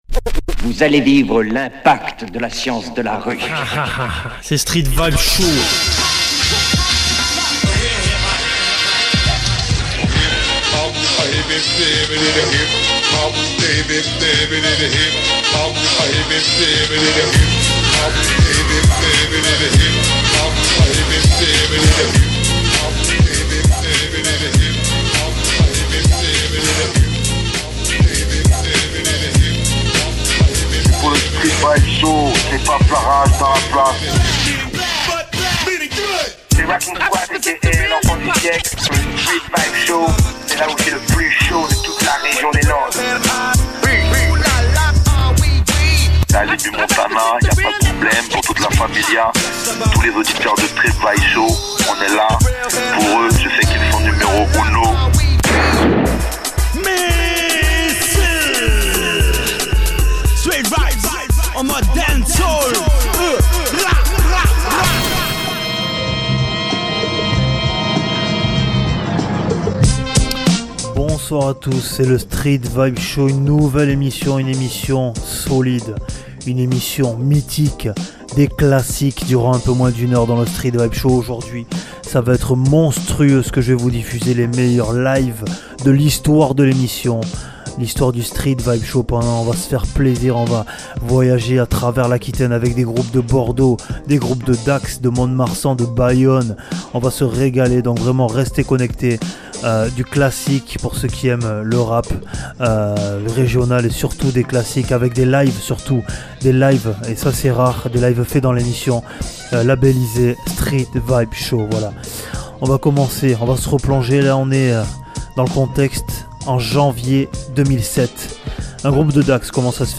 Le must de la musique Electro